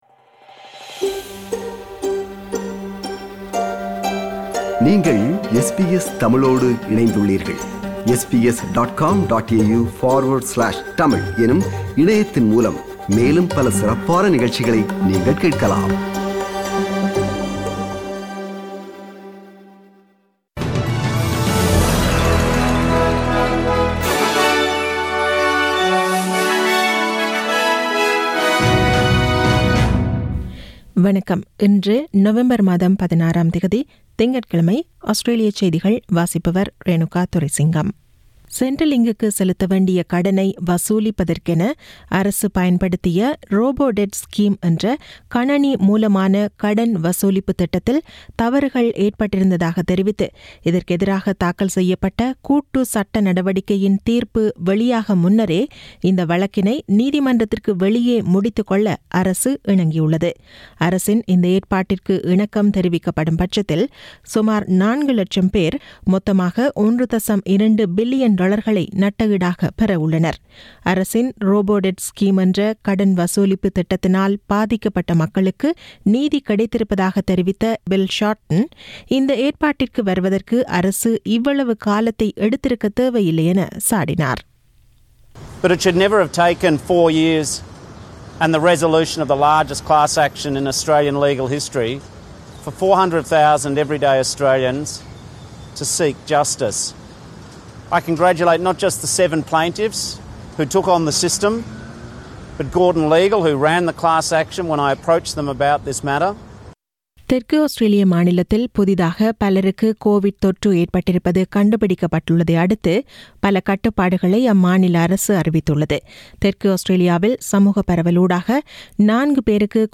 SBS தமிழ் ஒலிபரப்பின் இன்றைய (திங்கட்கிழமை 16/11/2020) ஆஸ்திரேலியா குறித்த செய்திகள்